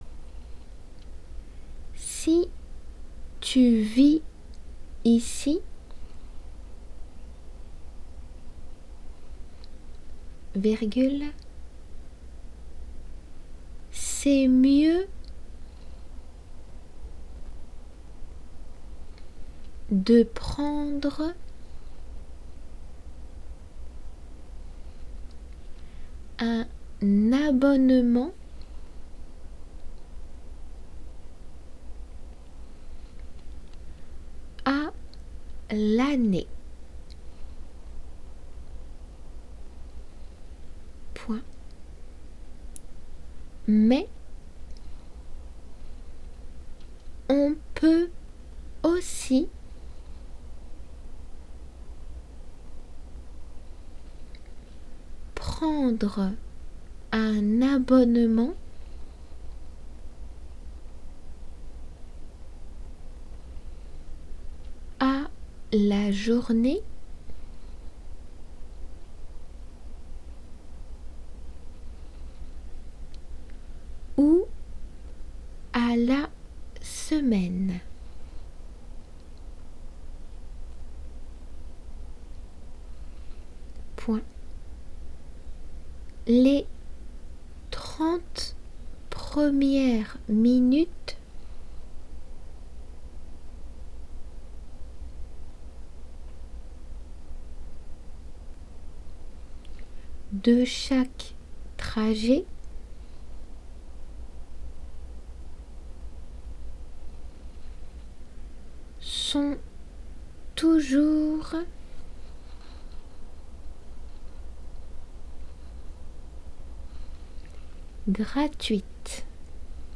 デイクテの速さで